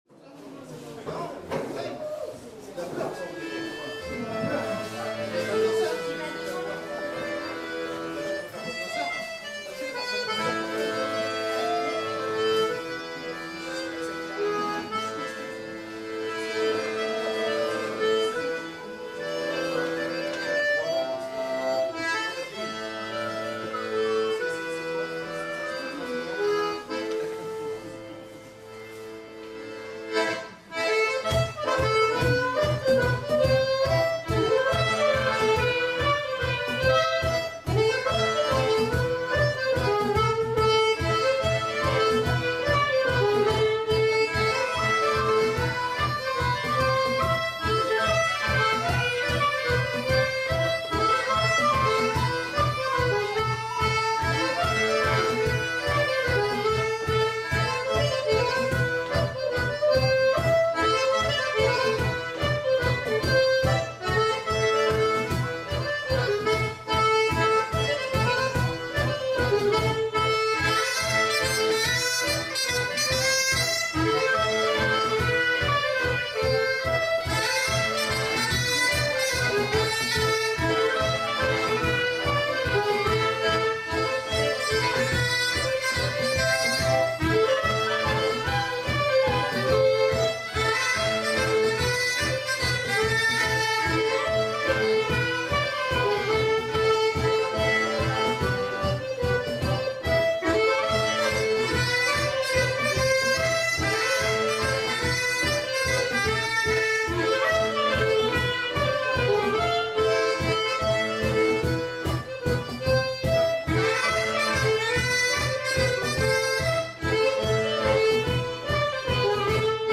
Dans Tro - Gavotte des montagnes
• le ton doubl (ton hir, « temps long »), plus vive et plus dynamique que la première partie) avec un phrasé musical redoublé.